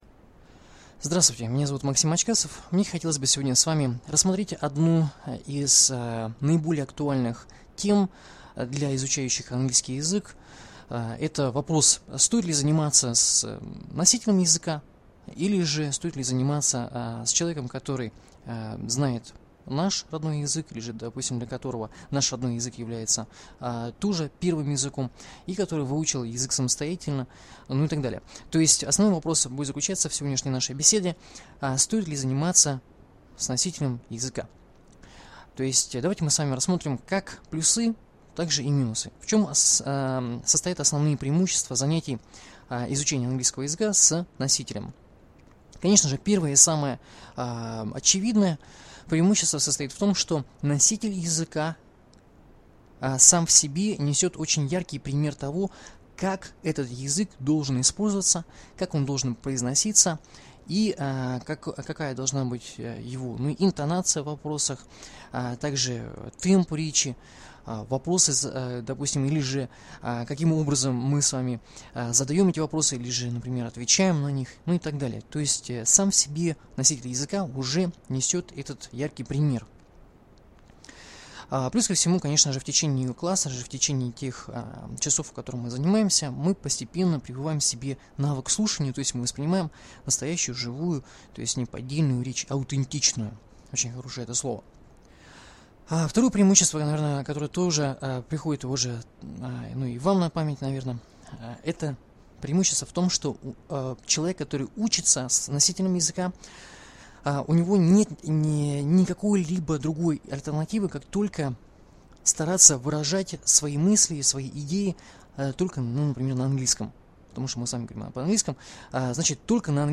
Learning English with a native speaker